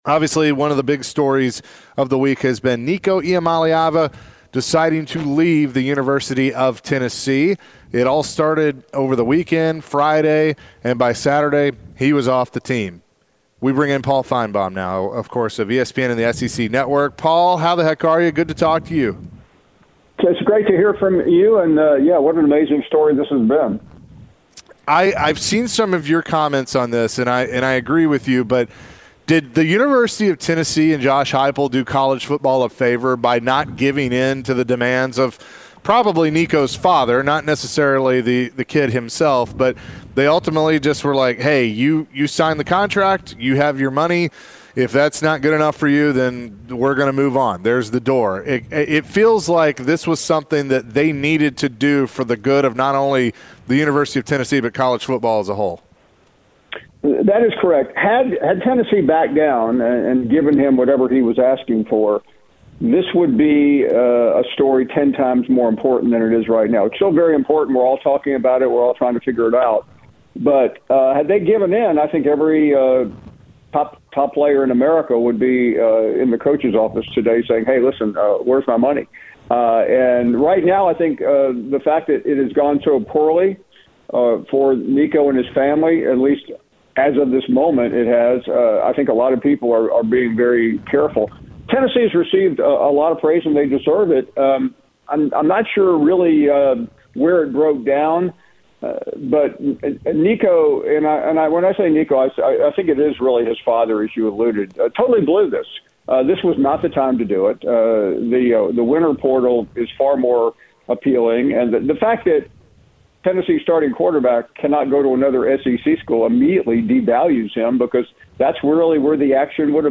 We get a deeper look into the Nico Iamaleava saga as SEC Network and CFB analyst Paul Finebaum joined the show. Did Tennessee do the right thing by letting Nico walk? Will Tennessee fans understand why the Vols might have a bad season if they only win 6-7 games?